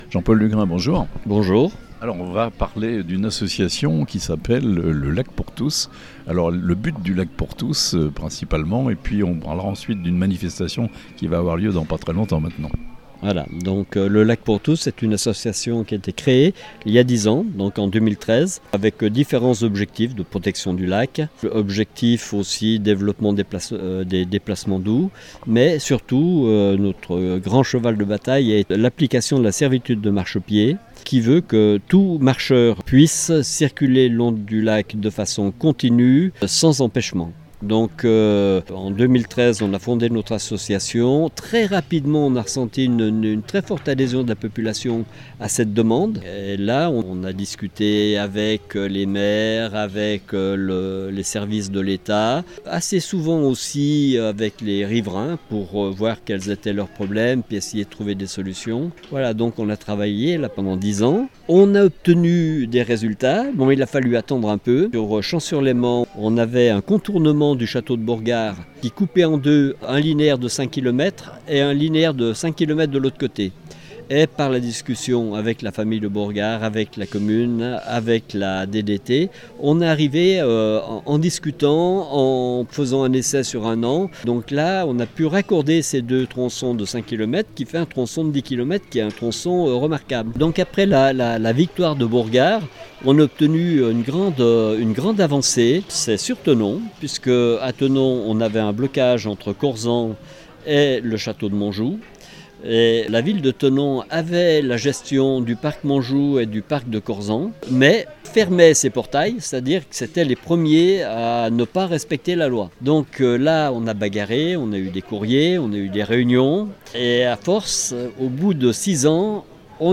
La création d'un sentier littoral libre le long du Léman français, objectif de l'association "Le Lac pour Tous" (interview)